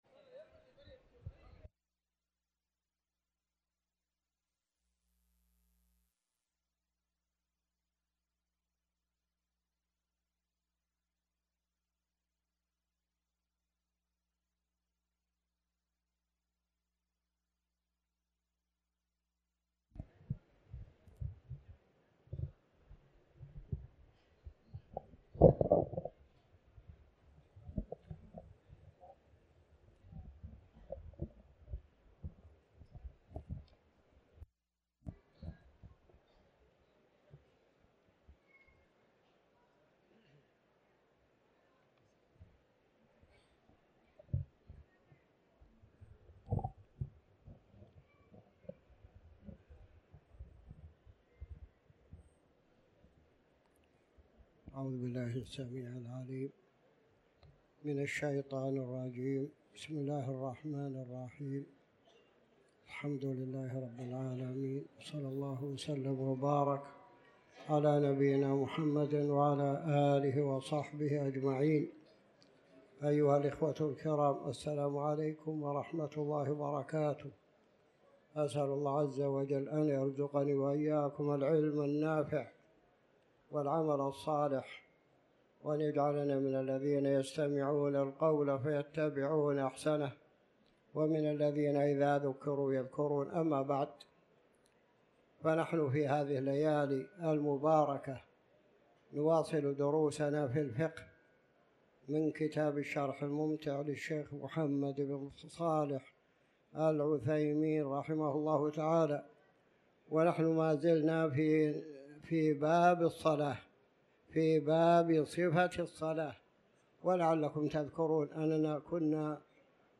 تاريخ النشر ١٧ ذو القعدة ١٤٤٠ هـ المكان: المسجد الحرام الشيخ